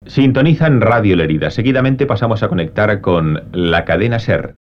Identificació de l'emissora i connexió amb la Cadena SER